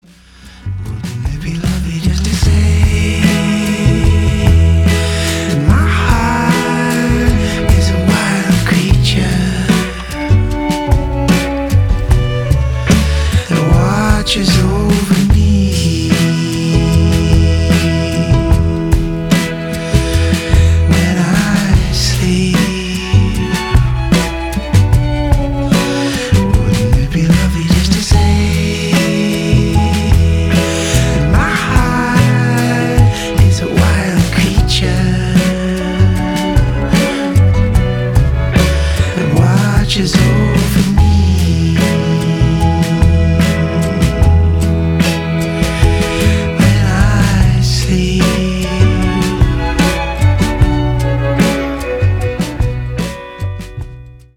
• Grunge
• Jazz
• Singer/songwriter
Poesi og mystic og ro og varme.